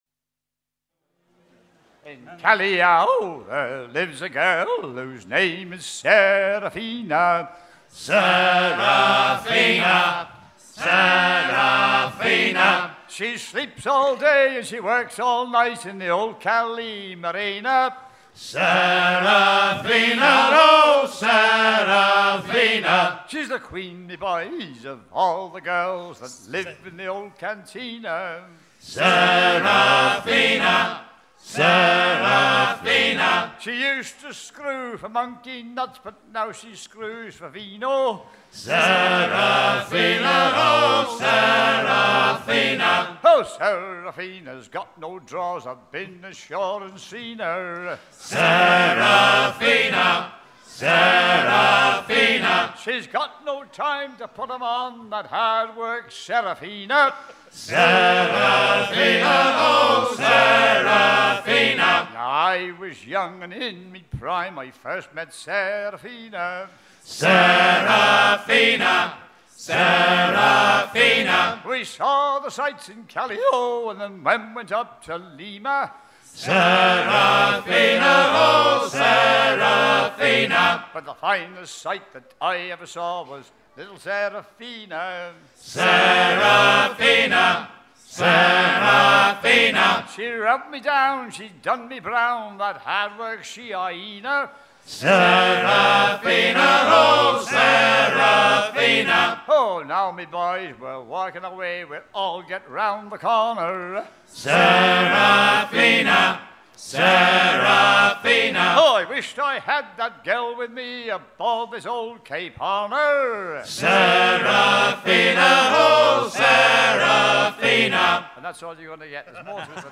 Chants à hisser les perroquet en usage à bord des navires faisant le commerce du salpêtre sur la côte Ouest de l'Amérique du Sud
à hisser main sur main
Pièce musicale éditée